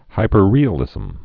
(hīpər-rēə-lĭzəm)